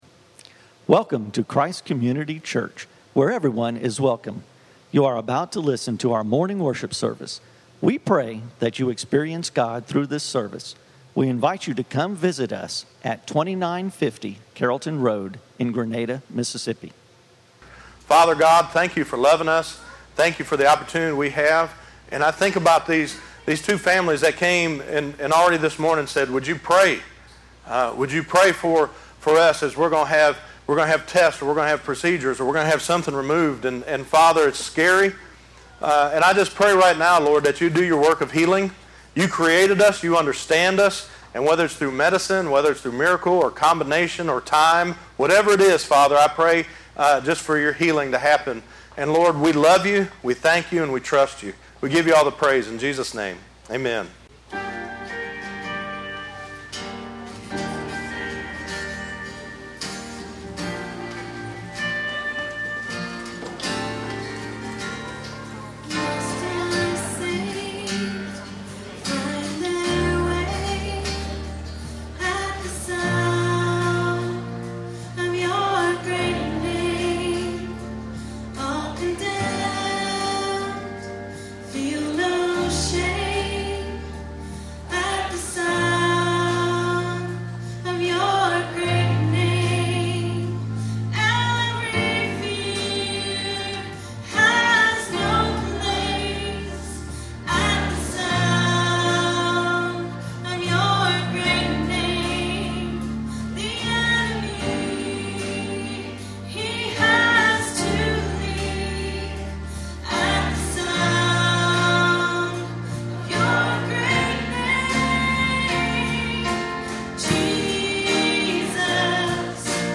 Listen to Message